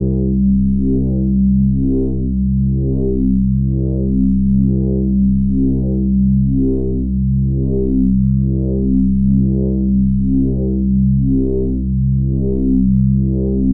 Juicy Analog Bass.wav